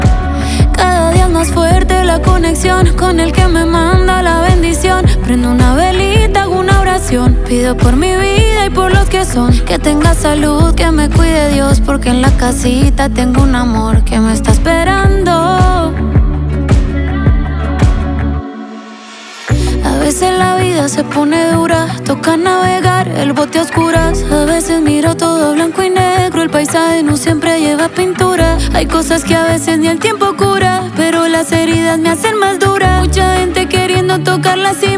Скачать припев
Latin